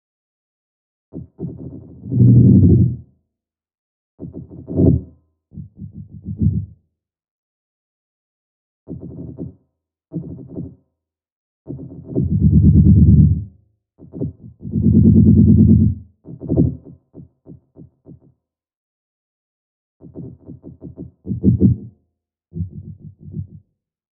Weird Sounds At Night Fantasy Sfx